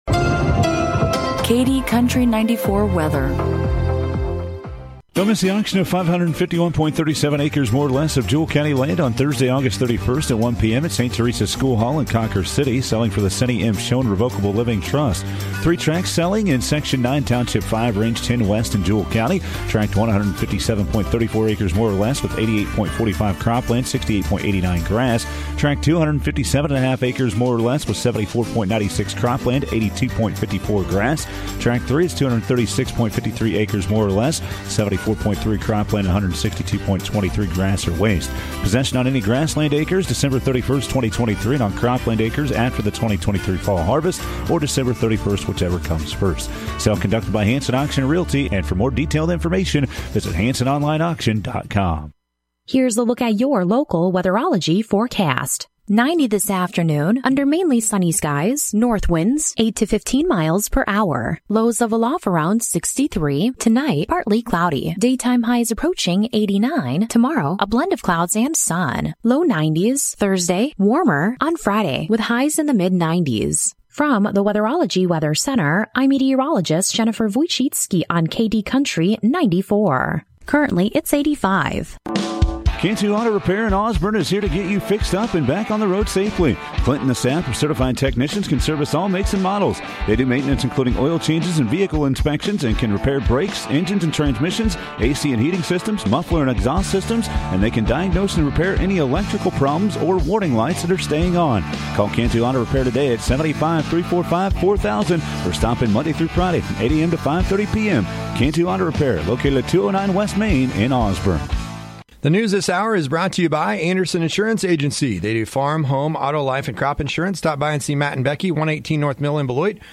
KD Country 94 Local News, Weather & Sports - 8/29/2023